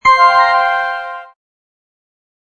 Blue Flute.mp3